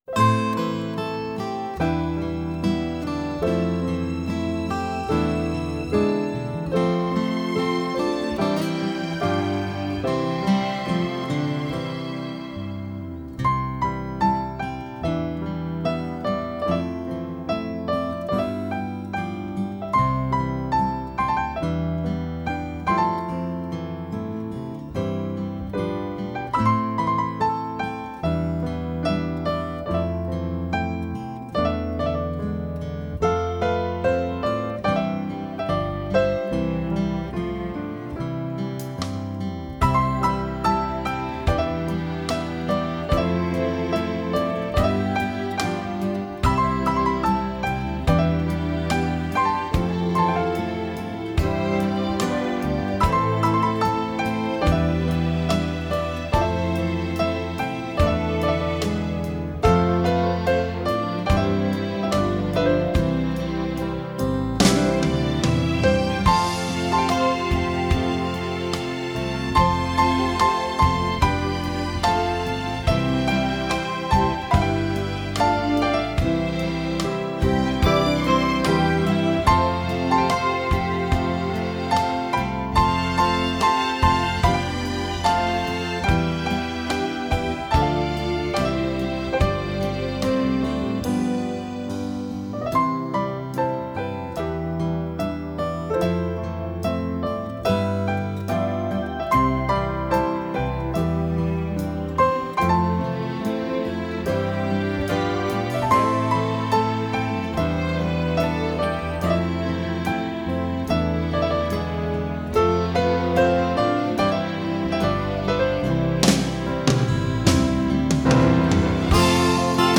Жанр: Easy Listening,Pop-Jazz